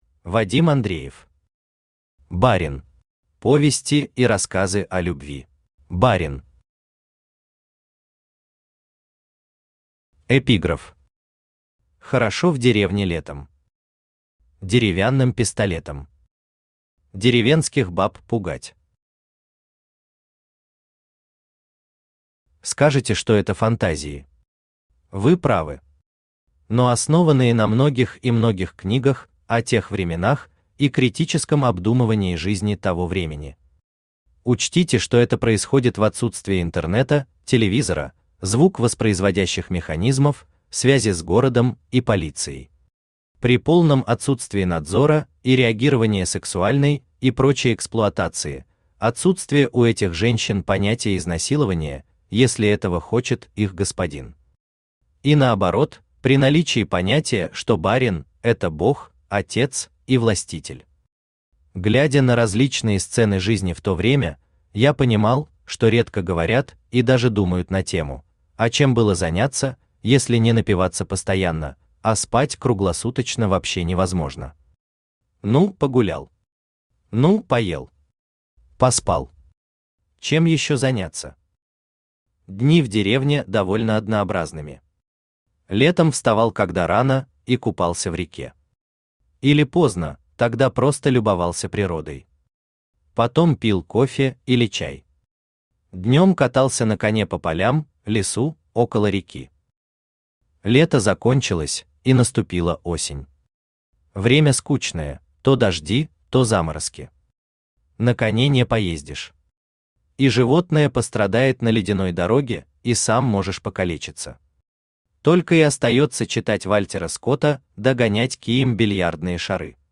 Повести и рассказы о любви Автор Вадим Андреев Читает аудиокнигу Авточтец ЛитРес.